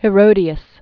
(hĭ-rōdē-əs) Died c. AD 39.